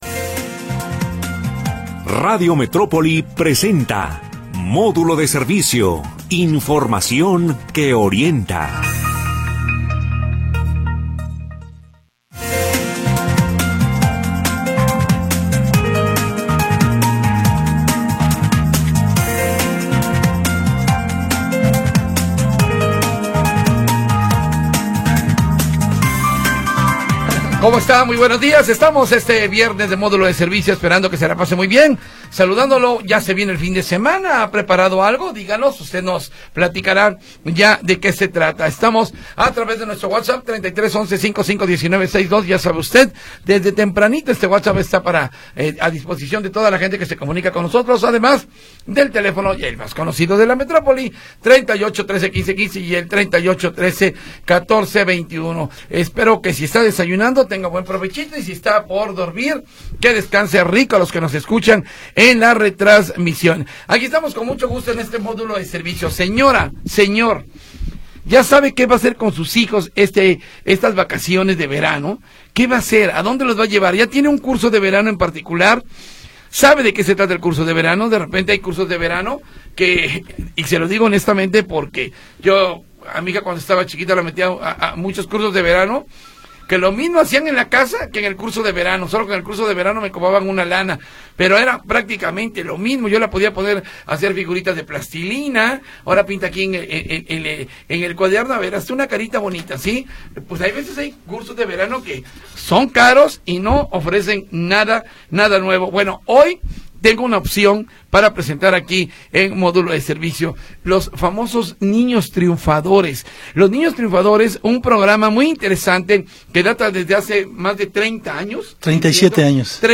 Programa transmitido el 11 de Julio de 2025.